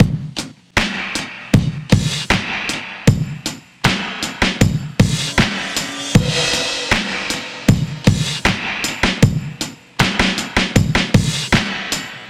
Track 11 - Drum Break 03.wav